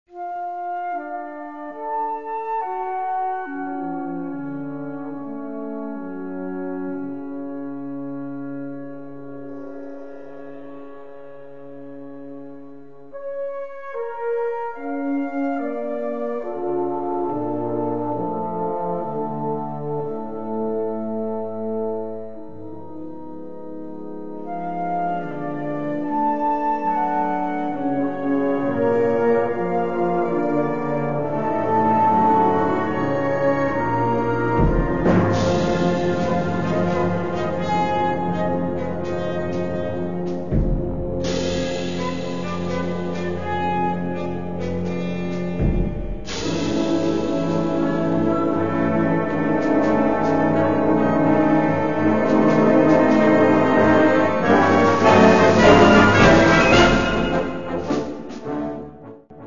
Gattung: Sonata
Besetzung: Blasorchester